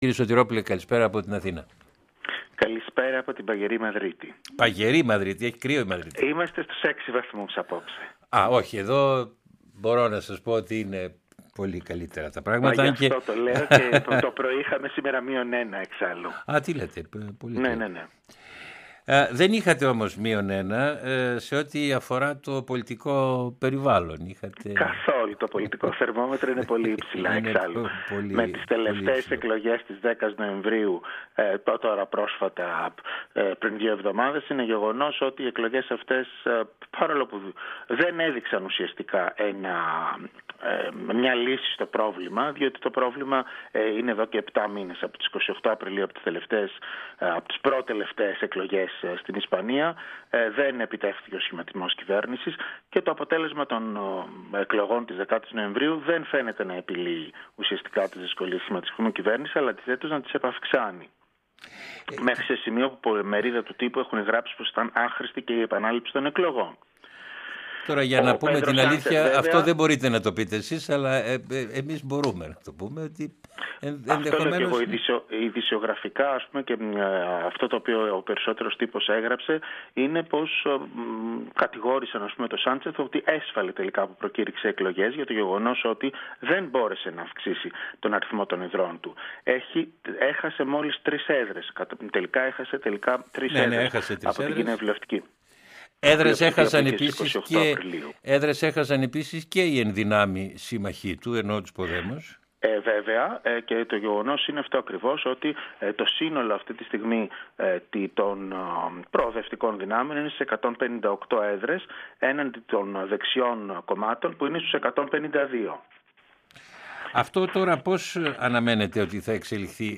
Συνέντευξη για τις τελευταίες εκλογές στην Ισπανία (10.11.19) και τους μετεκλογικούς συσχετισμούς δυνάμεων